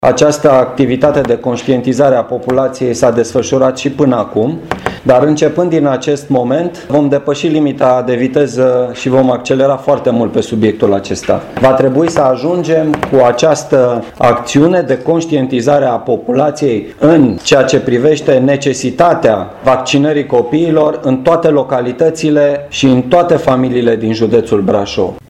Prefectura Brașov, Direcția de Sănătate Publică și Asociația Medicilor de Familie, au demarat o campanie susținută de conștientizare a importanței vaccinării. Prefectul Brașovului, Marian Rasaliu: